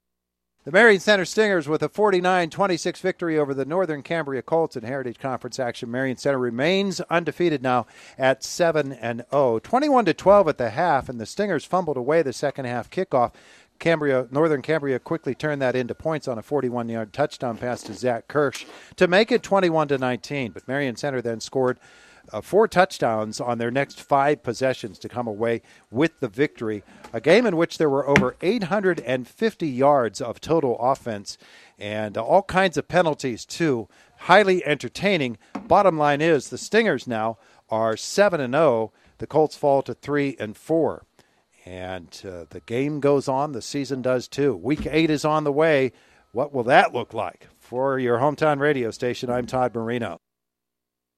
recap
hsfb-marion-center-recap-1.mp3